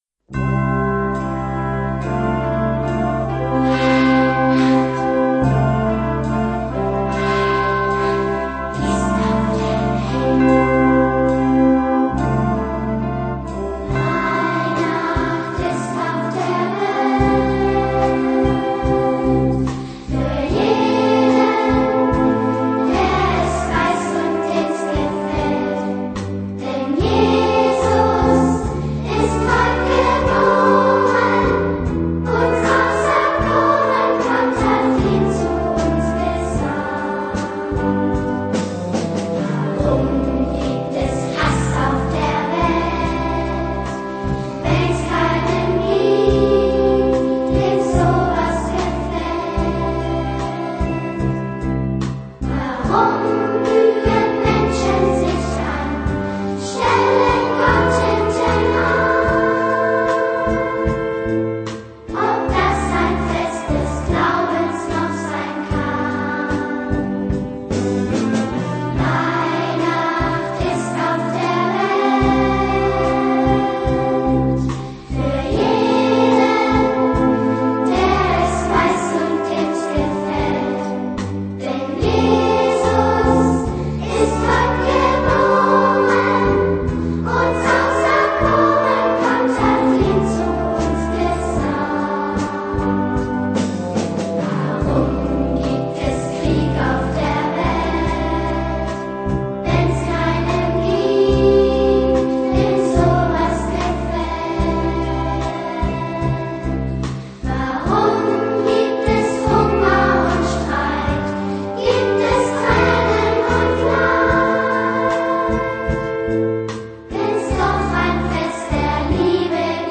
Modernes Singspiel für Kinderchor und Instrumentalensemble.